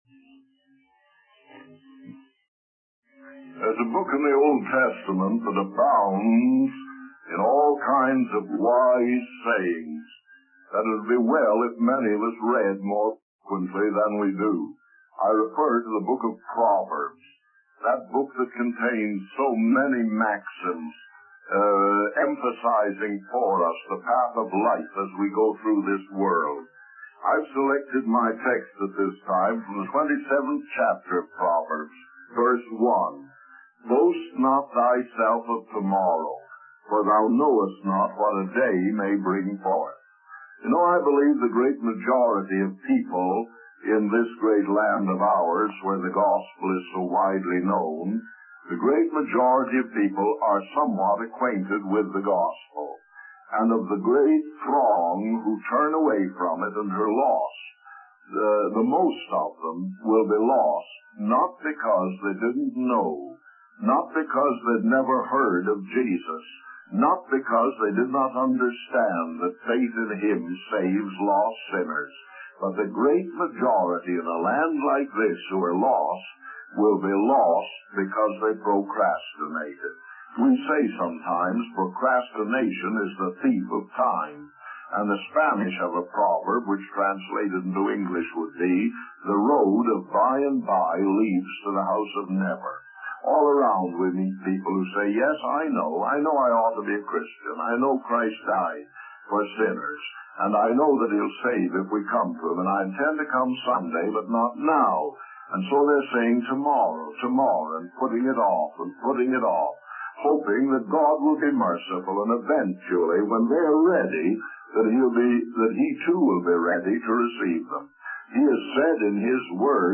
The sermon emphasizes the urgency of accepting Christ today rather than procrastinating for tomorrow, highlighting the dangers of delay and the certainty of eternity.